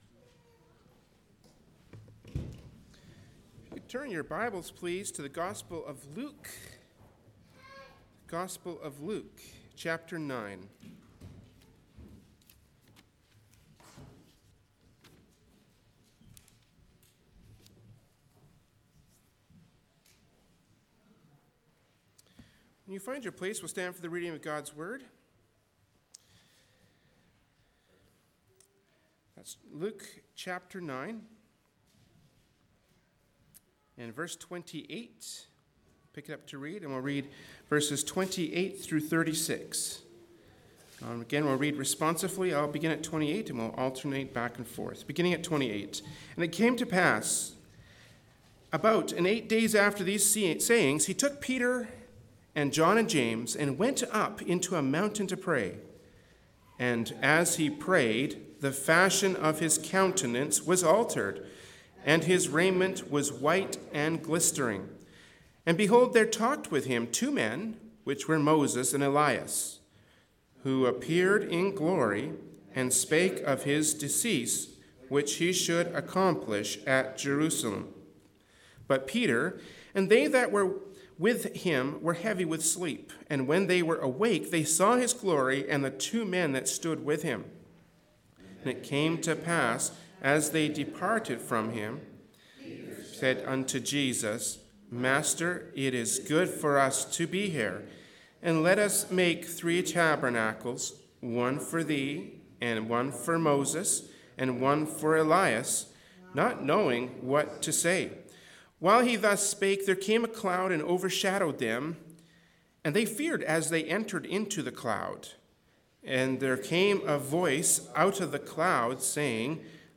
Genre: Preaching.
Service Type: Sunday Morning Worship Service